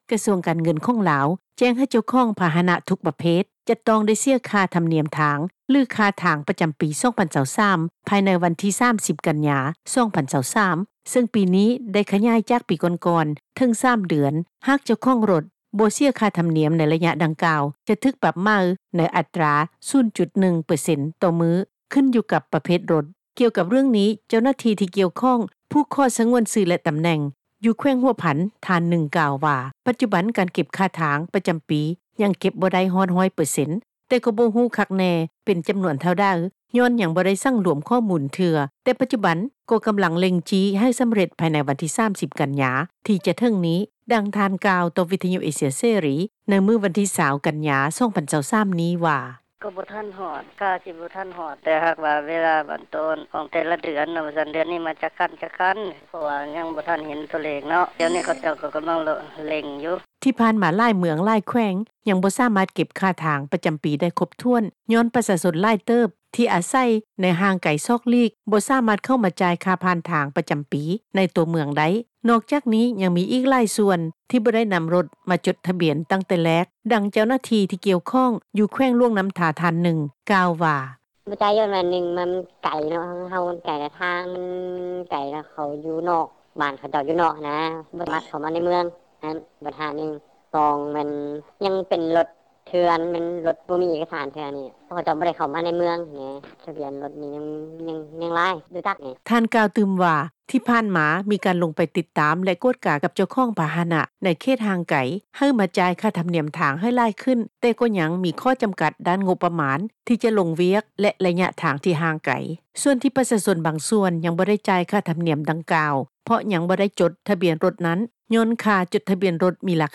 ດັ່ງເຈົ້າໜ້າທີ່ພະແນກ ໂຍທາທິການ ແລະ ຂົນສົ່ງ ນາງນຶ່ງ ກ່າວວ່າ:
ດັ່ງຊາວບ້ານຢູ່ນະຄອນຫລວງວຽງຈັນ ທ່ານນຶ່ງ ກ່າວວ່າ:
ດັ່ງ ຊາວບ້ານ ຢູ່ເມືອງ ໄຊທານີ ທ່ານນຶ່ງ ກ່າວວ່າ: